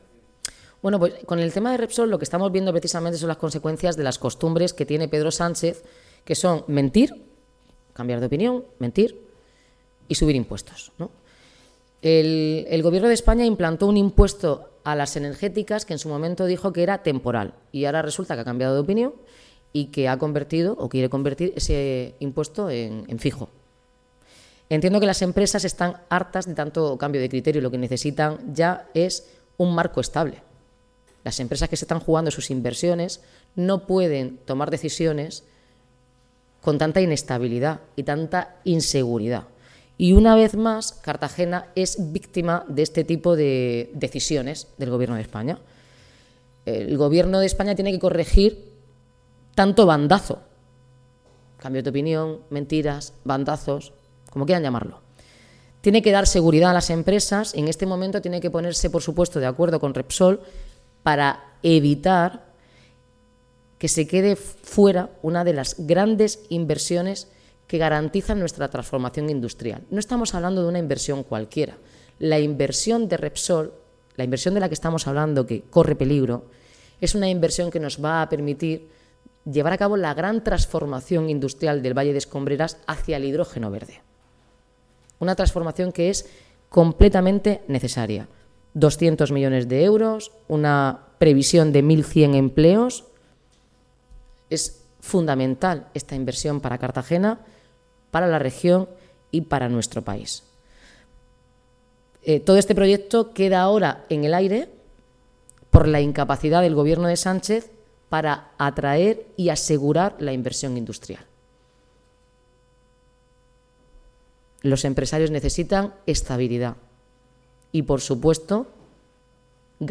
Enlace a Declaraciones de Noelia Arroyo ante las preguntas de los medios de comunicación por las inversiones en Repsol.